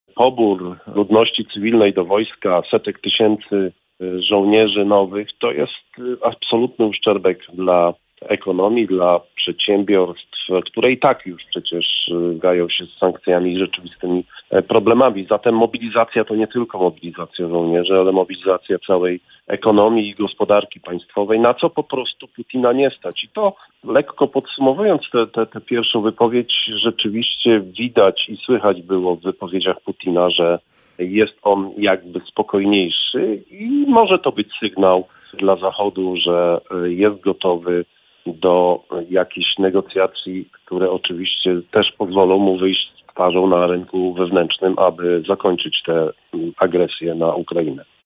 Przemówienie prezydenta Rosji komentował w audycji „Poranny Gość” gen. Jarosław Stróżyk były zastępca dyrektora Zarządu Wywiadu sztabu wojskowego NATO, były attaché w USA.